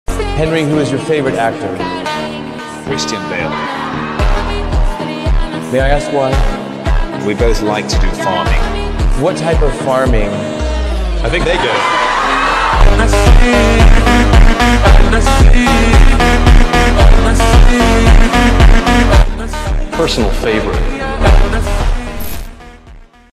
Slowed _ Reverb